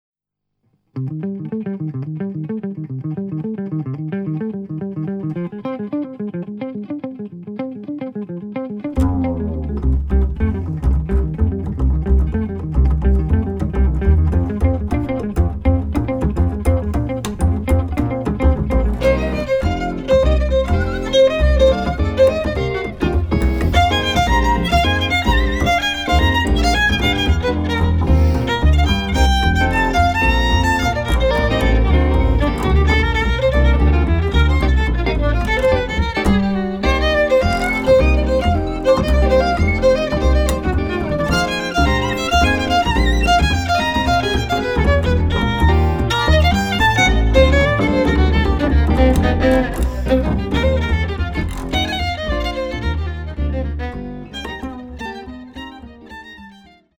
Violin
Bass
Cello